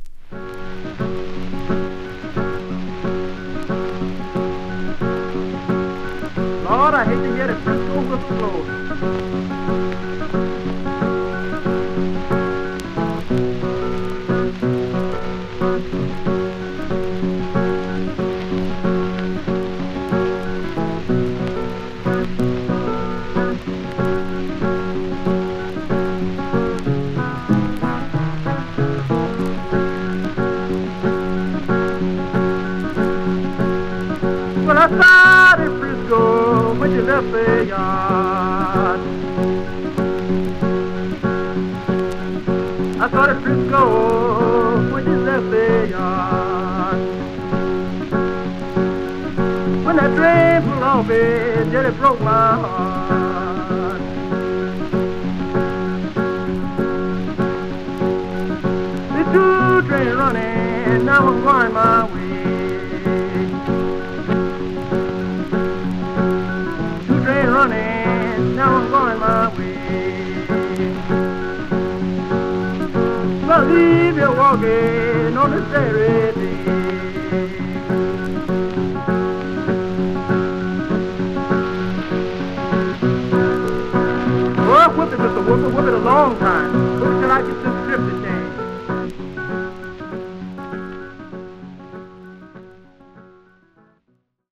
1927-31までにアラバマ州に残されたblues/folkを集めたオムニバス盤!